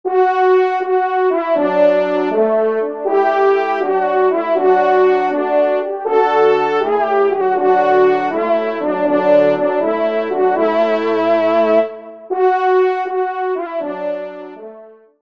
Genre : Fantaisie Liturgique pour quatre trompes
ENSEMBLE